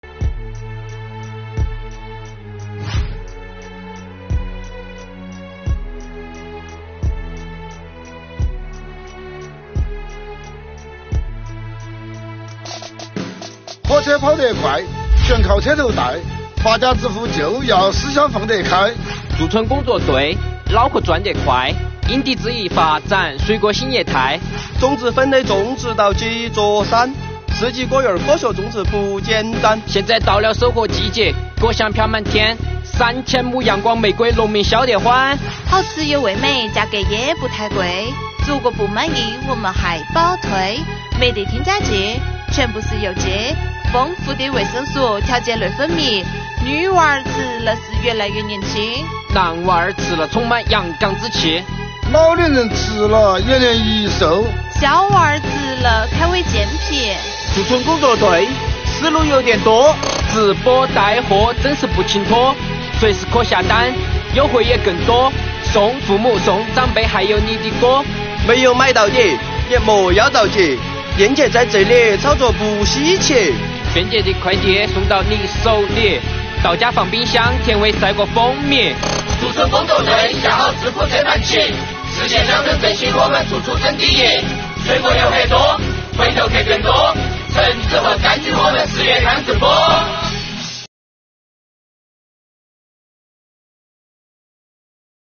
作品以助力乡村振兴为主线，讲述了税务局派驻工作队帮助村民推广优质农产品，携手村民开辟了一条发家致富路。作品采用新颖的说唱形式，结合土味方言，再配以丰富的音乐节奏，让人耳目一新，回味无穷。